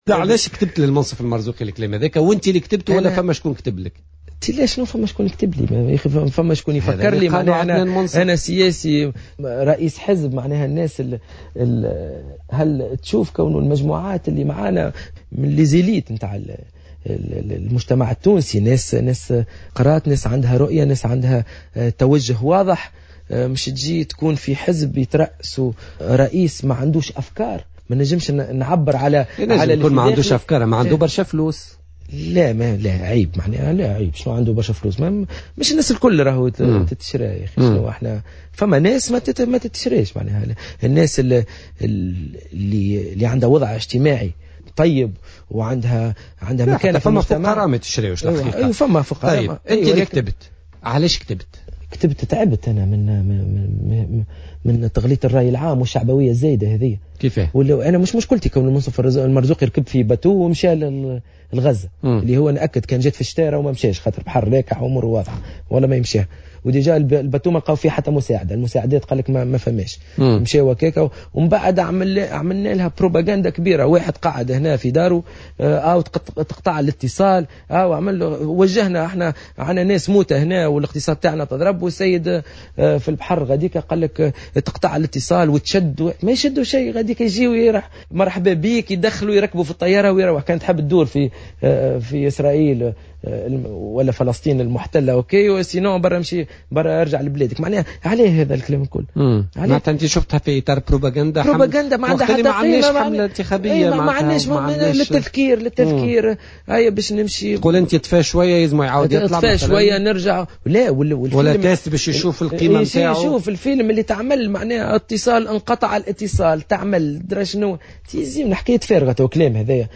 وقال لـ"جوهرة أف أم" في برنامج "بوليتيكا" إنه تم محاصرته عن طريق المكتب التنفيذي لمساندة المرزوقي في انتخابات 2014 الرئاسية.